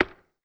BUTTON_Short_mono.wav